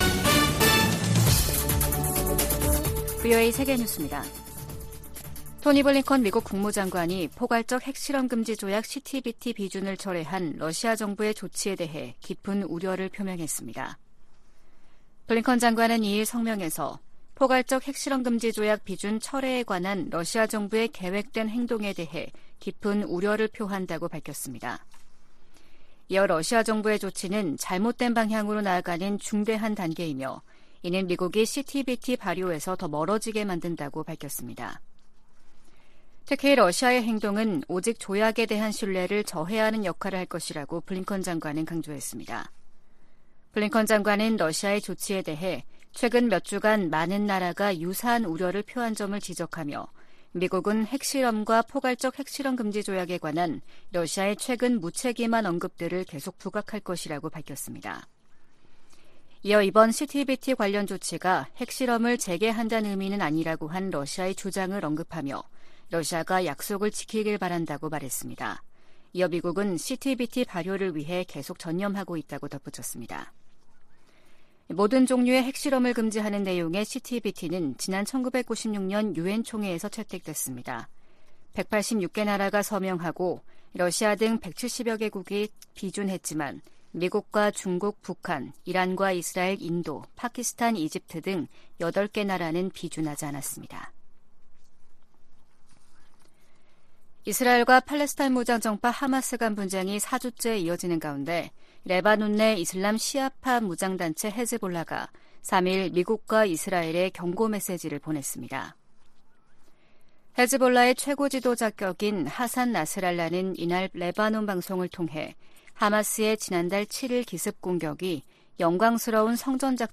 VOA 한국어 아침 뉴스 프로그램 '워싱턴 뉴스 광장' 2023년 11월 4일 방송입니다. 다음 주 한국을 방문하는 토니 블링컨 미 국무장관이 철통 같은 방위 공약을 강조할 것이라고 국무부가 밝혔습니다. 로이드 오스틴 국방장관도 잇따라 한국을 방문합니다.